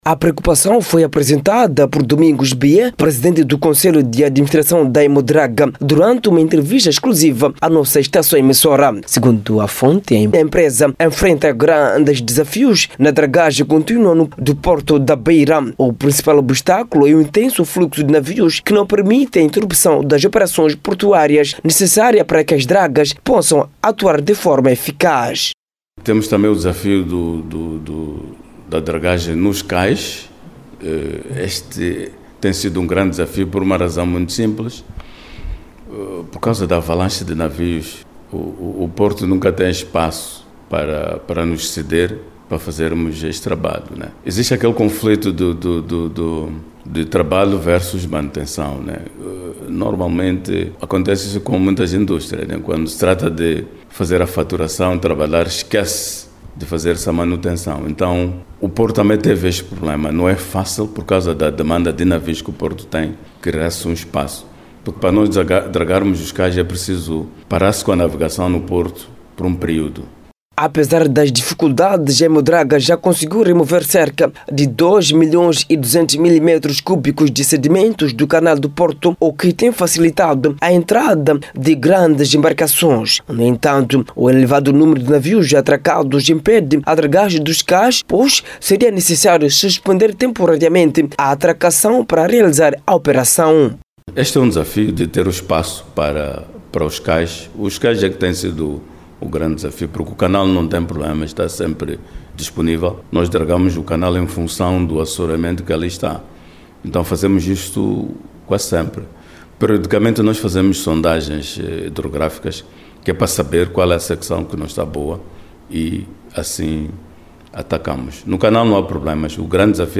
EMODRAGA-REPORTAGEM-EDITADO.mp3